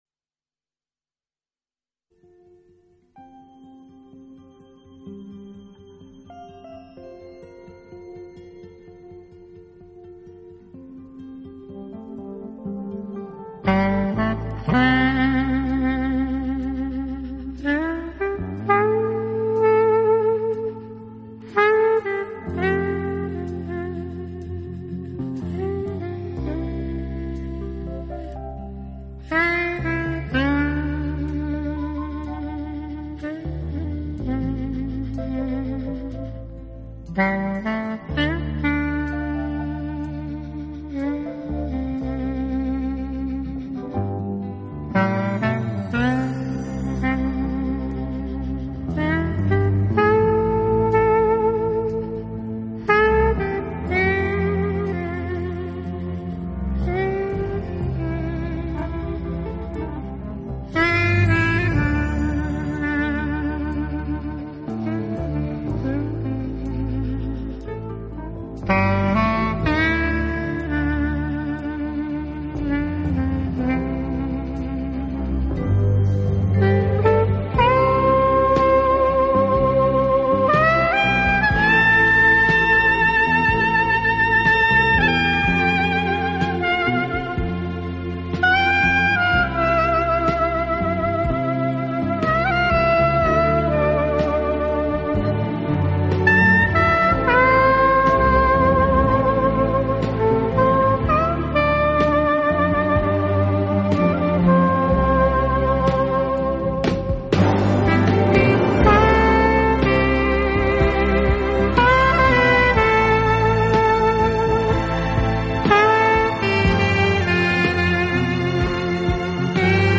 不同乐器演绎的世界名曲荟萃，给人耳目一新的美感，当优